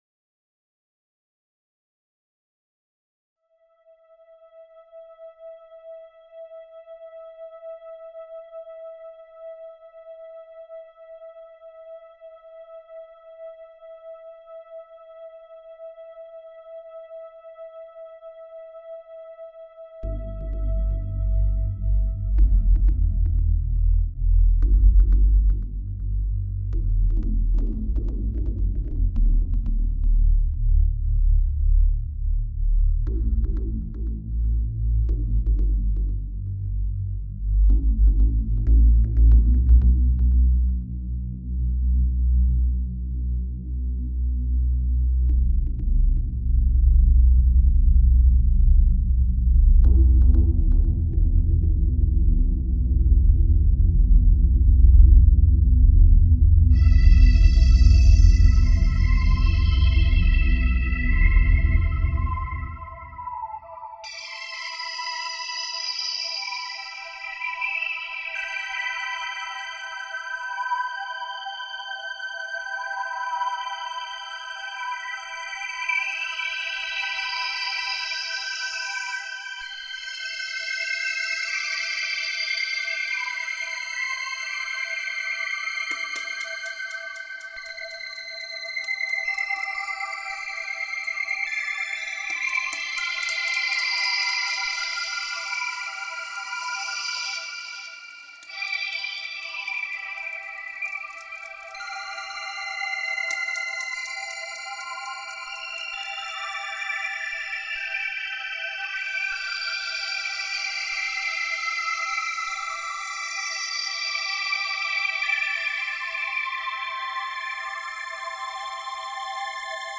Music / Trance
ambient new wave synth orchestral age trance psychedelic instrumental atmospheric